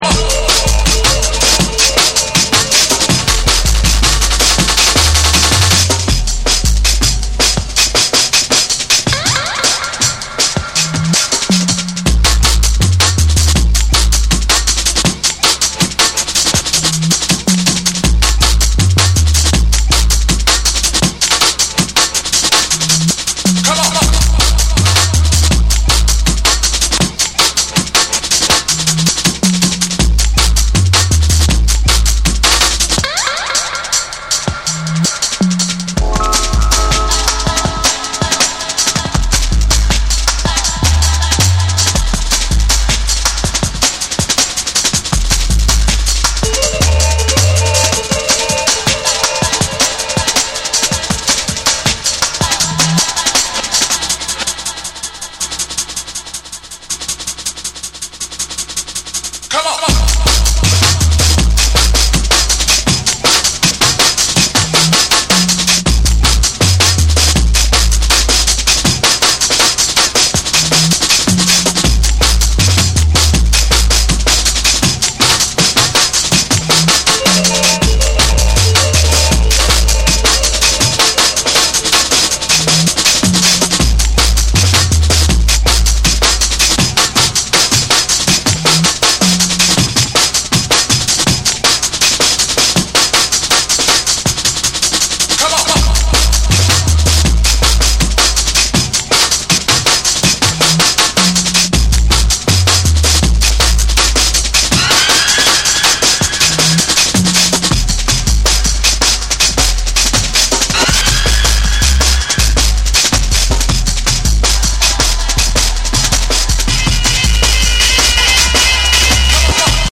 タフで荒削りなブレイクとヘヴィなサブベースが直撃するジャングルを収録。
JUNGLE & DRUM'N BASS